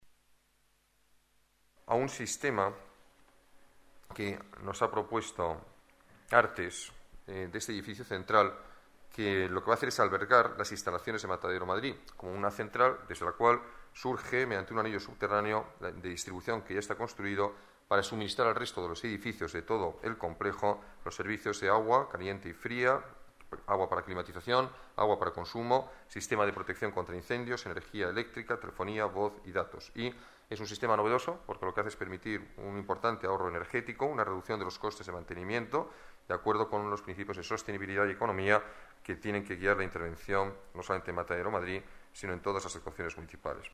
Nueva ventana:Declaraciones del alcalde sobre las actuaciones en Matadero